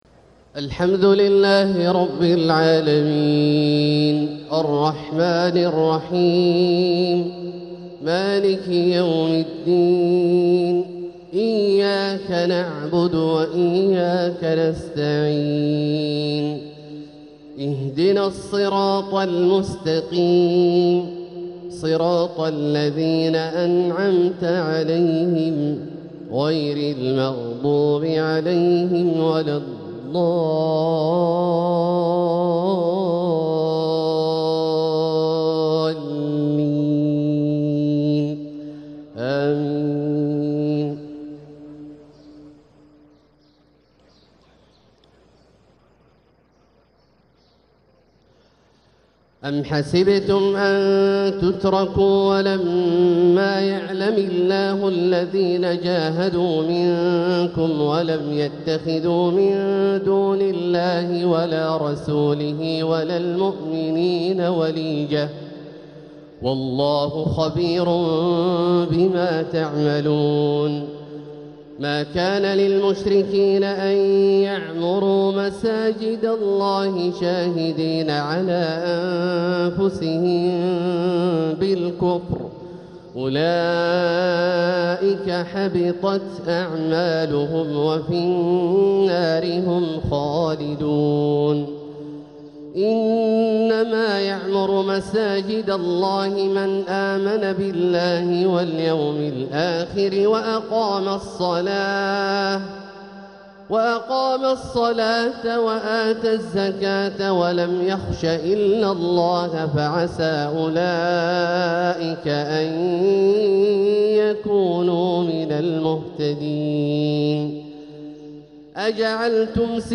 تلاوة من سورة التوبة 16-33 |فجر الإثنين 7 ربيع الآخر 1447هـ > ١٤٤٧هـ > الفروض - تلاوات عبدالله الجهني